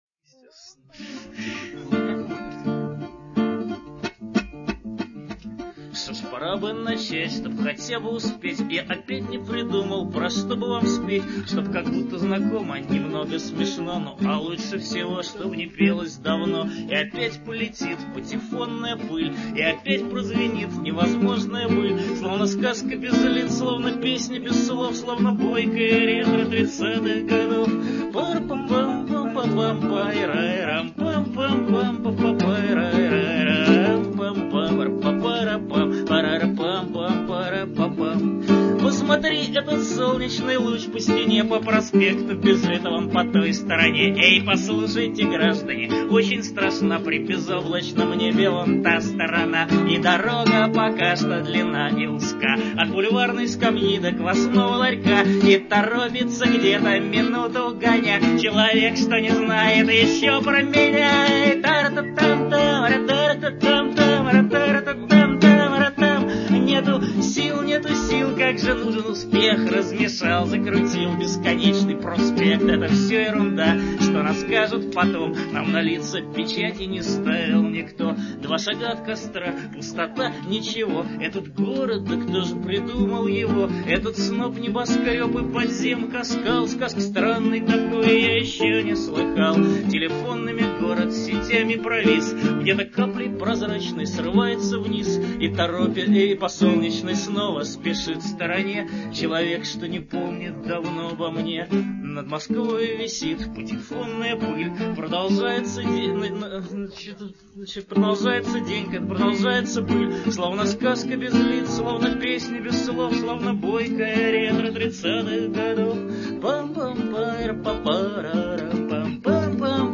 Сцена